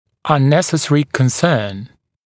[ʌn’nesəsərɪ kən’sɜːn][ан’нэсэсэри кэн’сё:н]необоснованное беспокойство